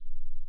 silence_loop.wav